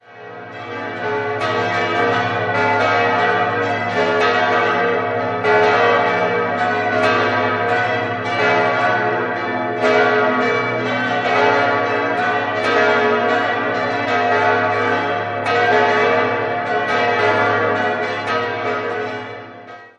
Zur künstlerischen Ausgestaltung wurden ausschließlich bekannte heimische Künstler herangezogen. 6-stimmiges Geläut: a°-h°-d'-fis'-a'-h' Die Glocken wurden 1958 von der Gießerei Grassmayr in Innsbruck gegossen.